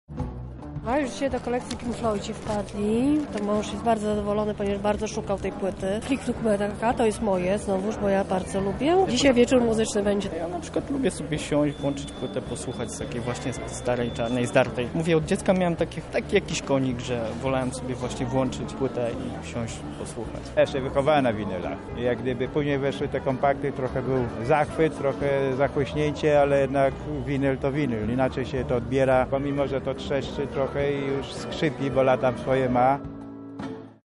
Giełda Płyt Winylowych odbyła się w niedzielę w Atrium Felicity.